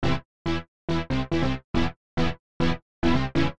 描述：有趣的小贝司 合成器循环。 150bpm。 8bars。
Tag: 电子 旋律 合成器 低音 有趣 时髦